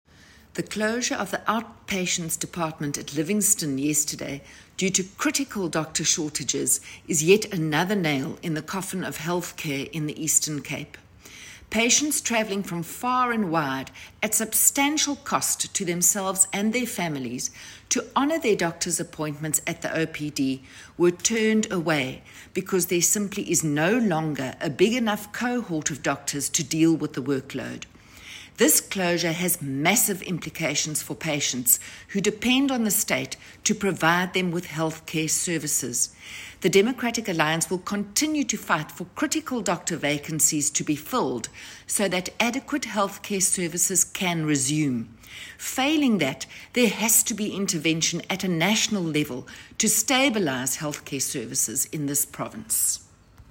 Issued by Jane Cowley MPL – DA Shadow MEC for Health